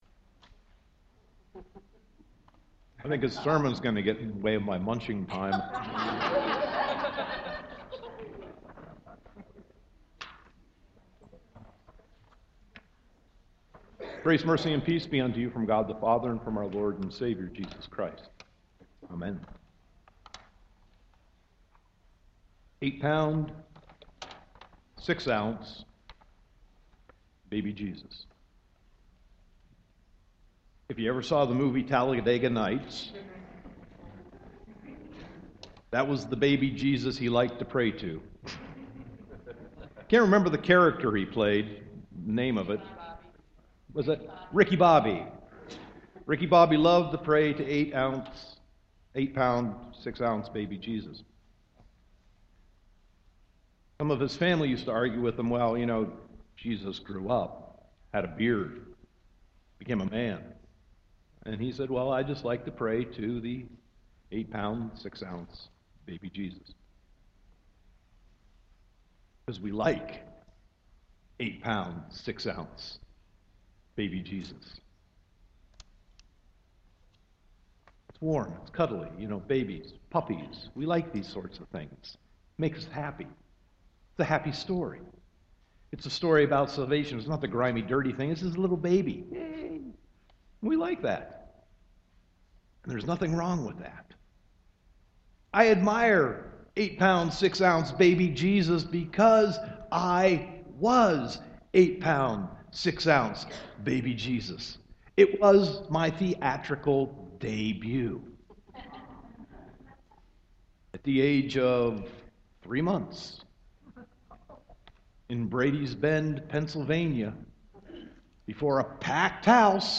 Sermon 12.24.2015